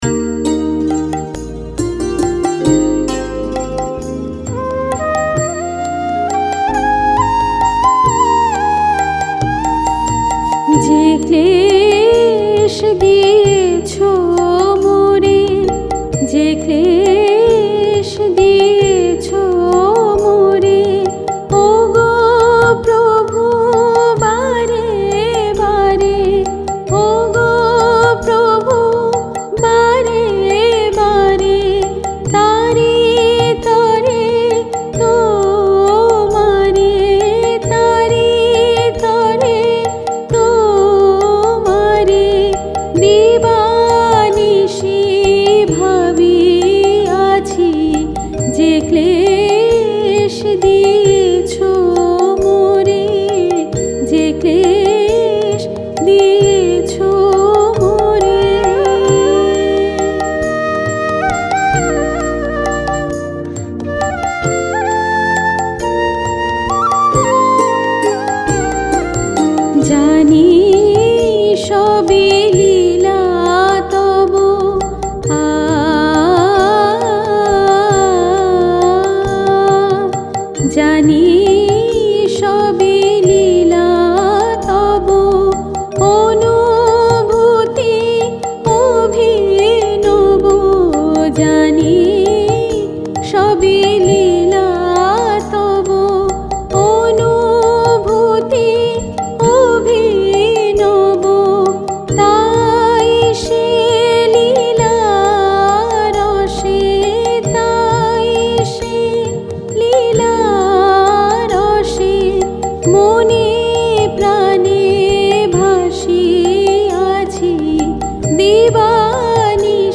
Music Dadra, Raga Asavari